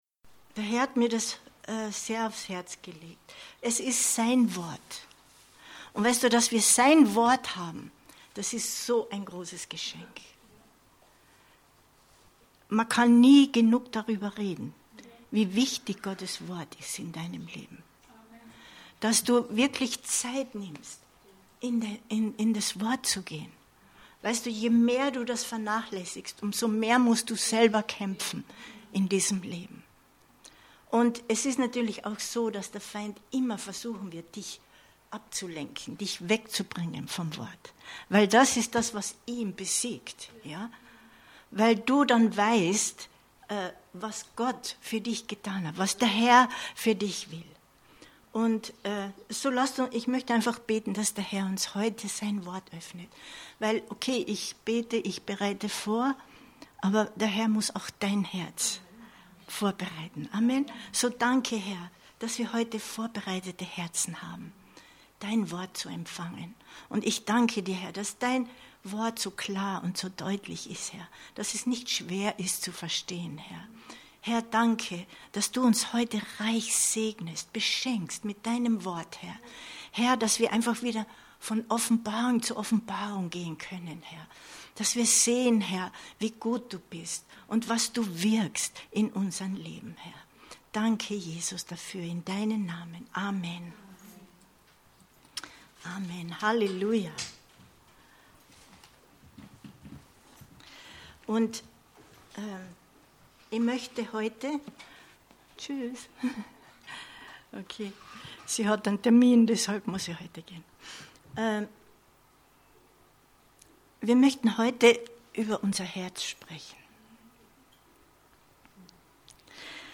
Info Info Behüte und bewahre dein Herz 15.05.2022 Predigt herunterladen